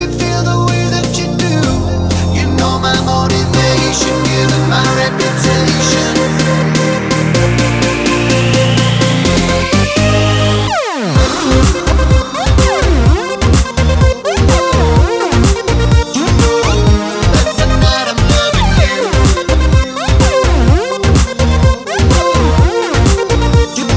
With Rapper Pop